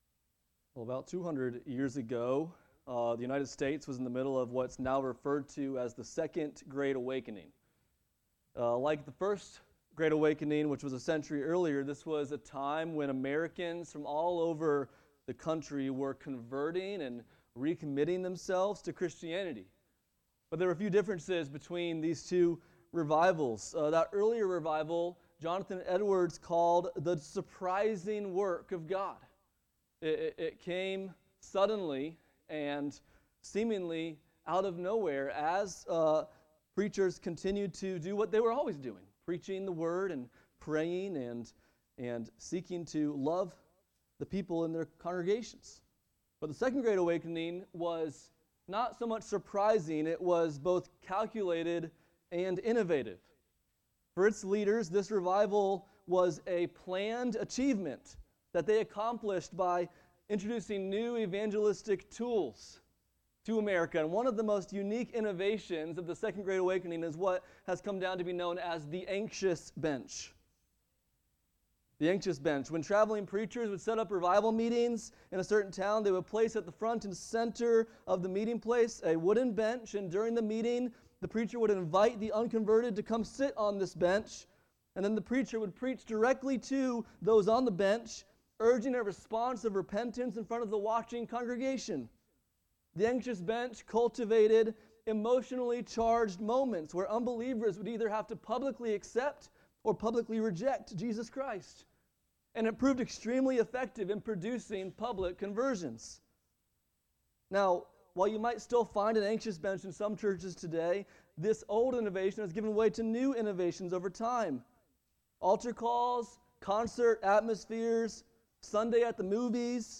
Passage: Acts 2:37-47 Service Type: Sunday Morning